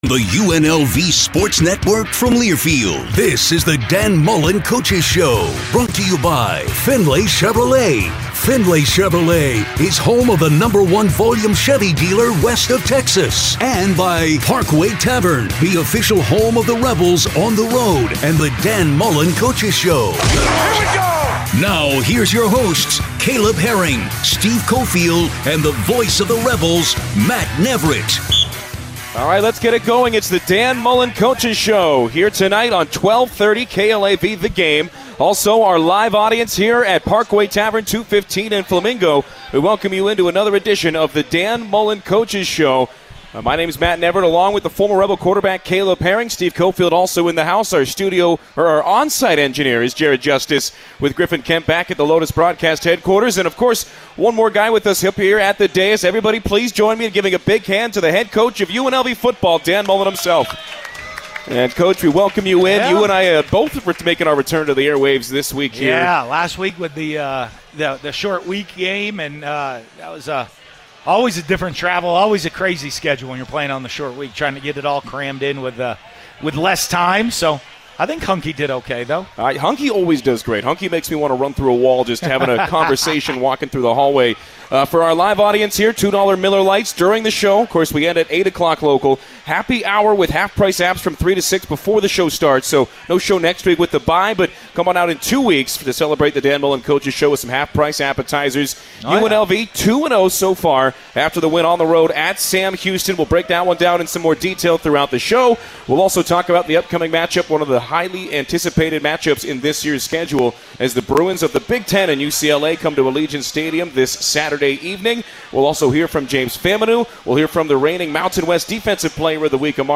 A weekly conversation with UNLV's Head Coach.
9-04-dan-mullen-radio-show.mp3